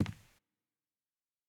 FootstepW2Left-12db.wav